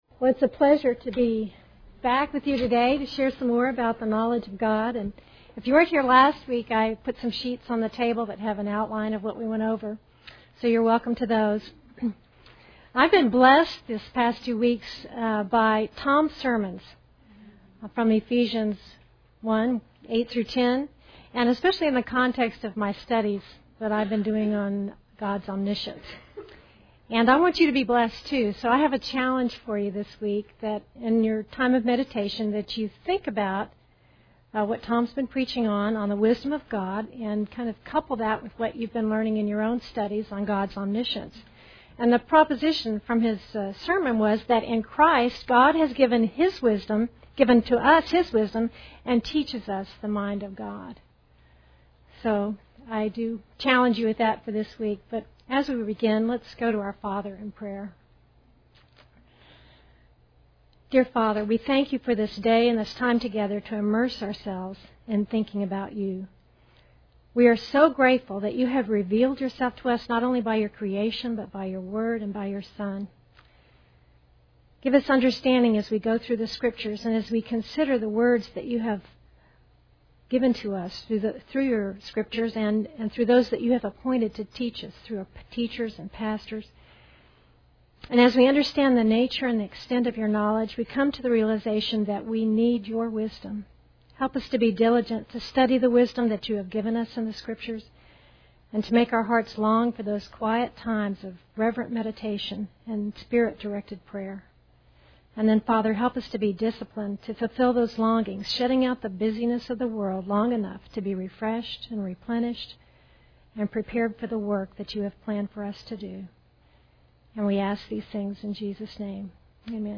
Women Women - Bible Study